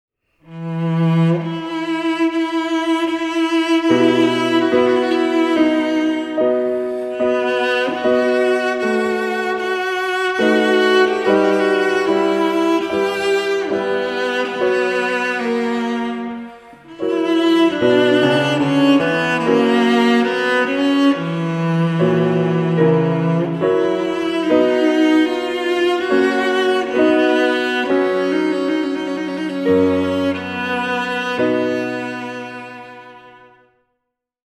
groep5_les1-4-2_strijkinstrumenten1.mp3